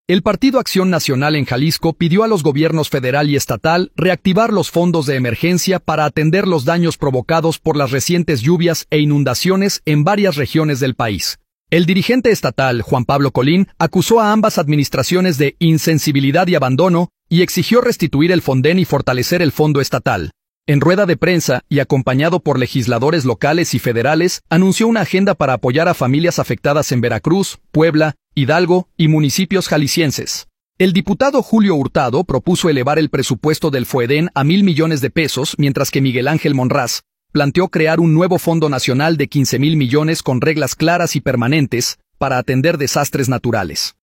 En rueda de prensa, y acompañado por legisladores locales y federales, anunció una agenda para apoyar a familias afectadas en Veracruz, Puebla, Hidalgo y municipios jaliscienses. El diputado Julio Hurtado propuso elevar el presupuesto del FOEDEN a mil millones de pesos, mientras que Miguel Ángel Monraz planteó crear un nuevo fondo nacional de 15 mil millones con reglas claras y permanentes para atender desastres naturales.